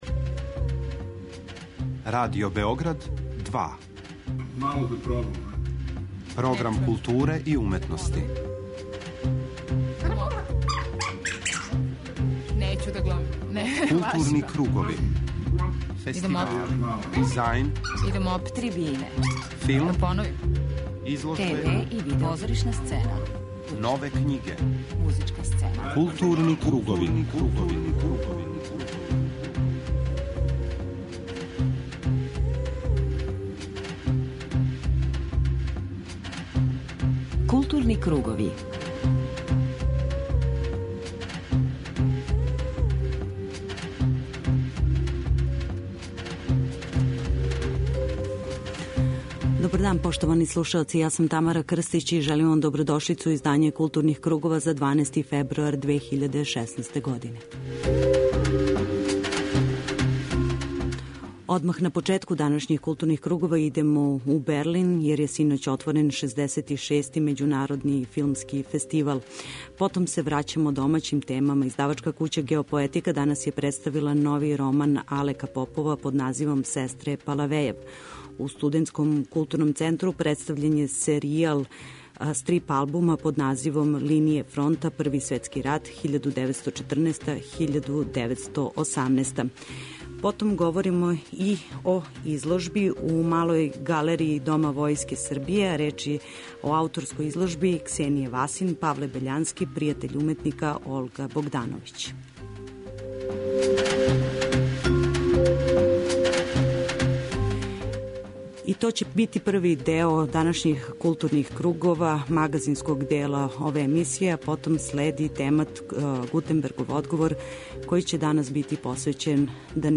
Разговор који ћете слушати снимљен је поводом објављивања књиге "Списак будућих покојника", у "Плавом колу" Српске књижевне задруге.